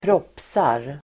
Ladda ner uttalet
Uttal: [²pr'åp:sar]